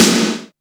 SC PWR SNARE.wav